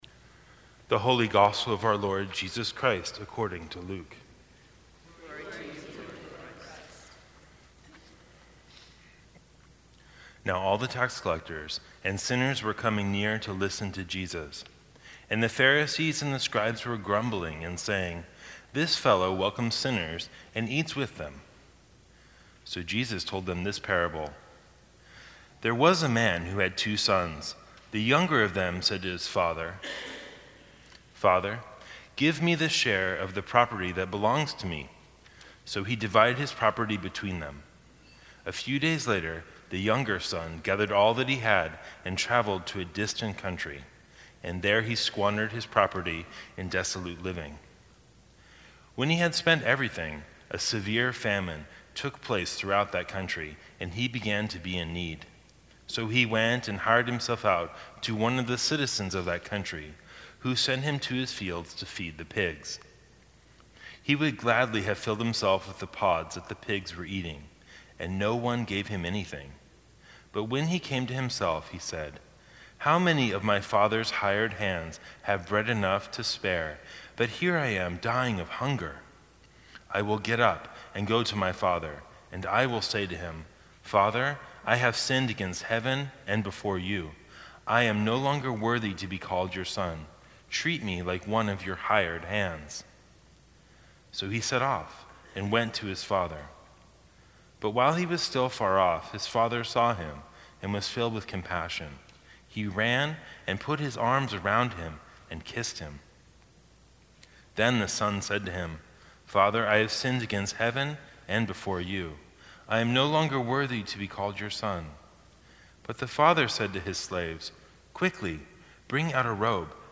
Sermons from St. Cross Episcopal Church The Parable of the Loving Father Apr 01 2019 | 00:15:14 1x 00:00 / 00:15:14 Subscribe Share Apple Podcasts Spotify Overcast RSS Feed Share Link Embed